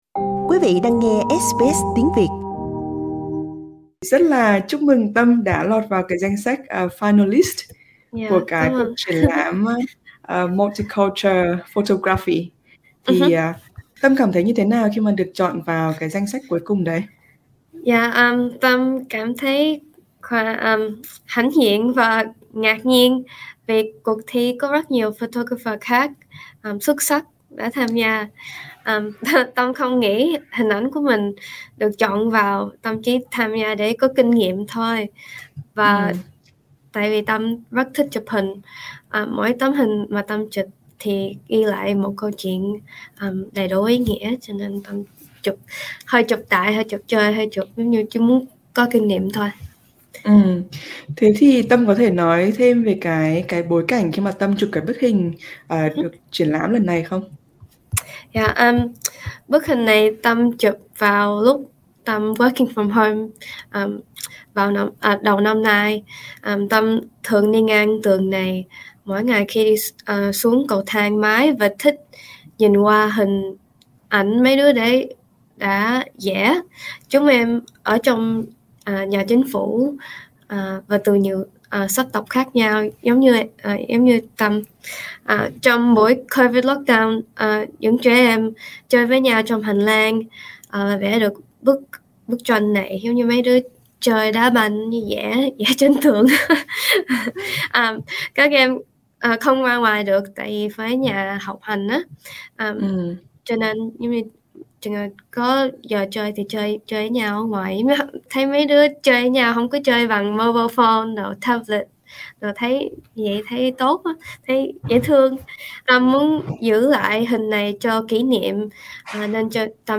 Xin mời quý vị bấm vào hình để nghe toàn bộ nội dung cuộc trò chuyện.